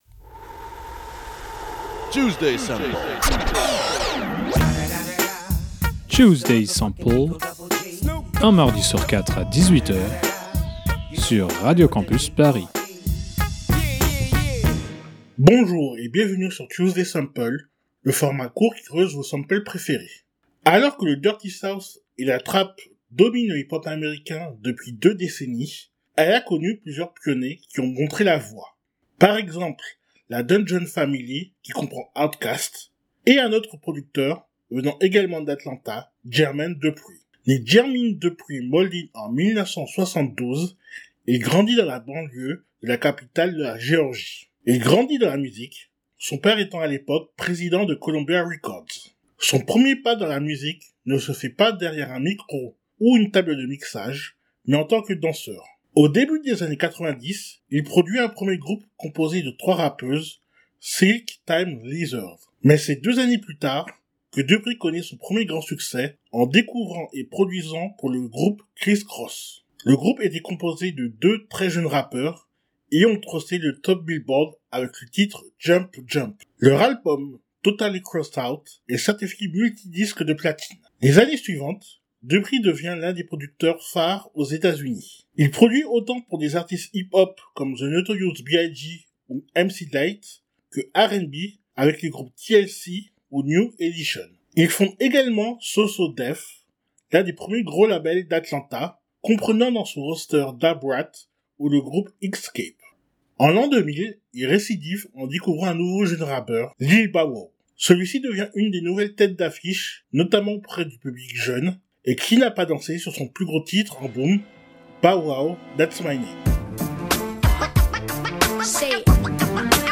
Type Musicale Hip-hop